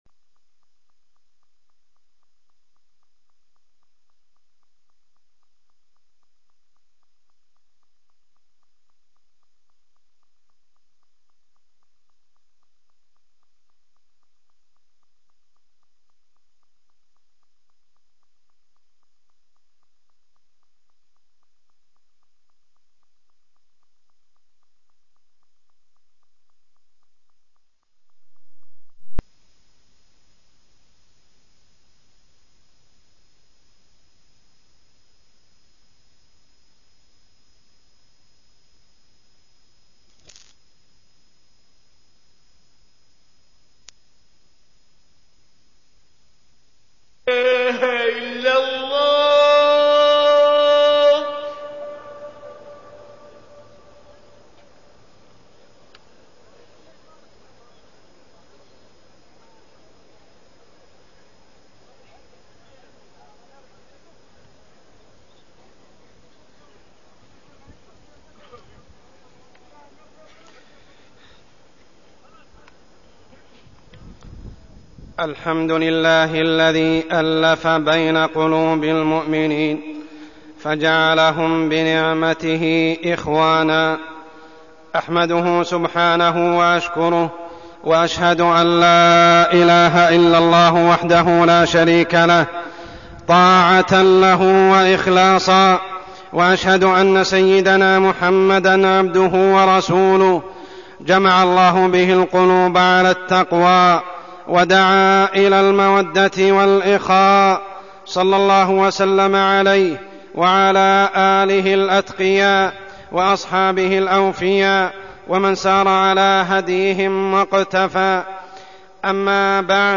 تاريخ النشر ١٩ ذو القعدة ١٤١٧ هـ المكان: المسجد الحرام الشيخ: عمر السبيل عمر السبيل العدوان على أهل فلسطين